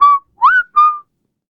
Kibera-Vox_Hum_a.wav